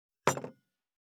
235,カチ,タン,バタン,スッ,サッ,コン,ペタ,パタ,チョン,コス,カラン,ドン,チャリン,効果音,環境音,BGM,
コップ効果音厨房/台所/レストラン/kitchen物を置く食器
コップ